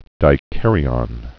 (dī-kărē-ŏn, -ən)